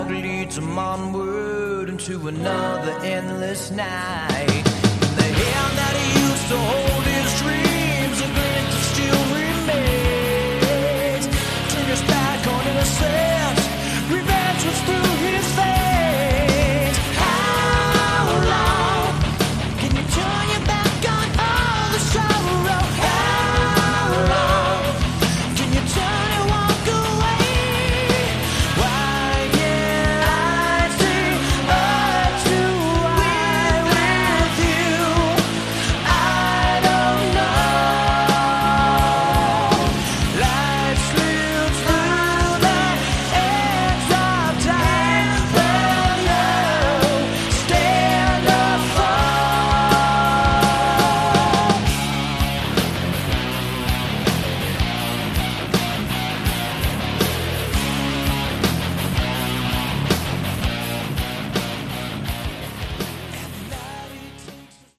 Category: Melodic Metal
guitar, keyboards and vocals